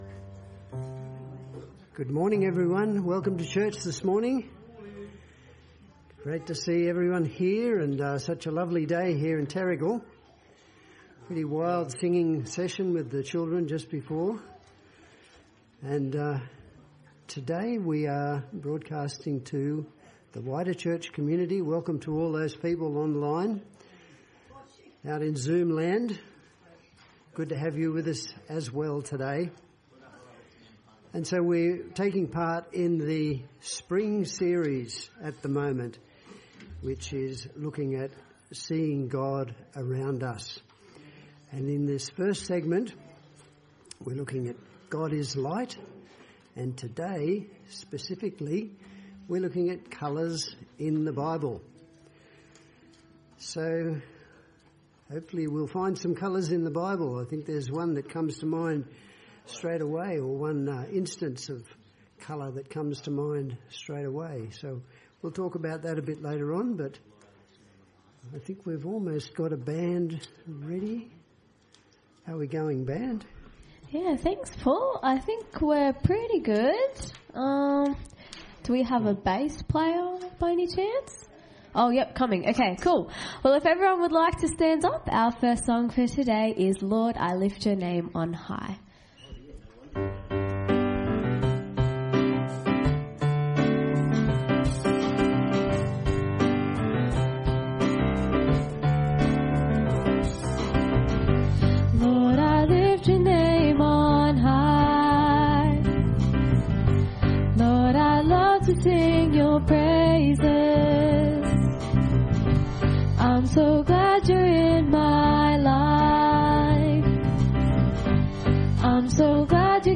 Service Type: Sunday Church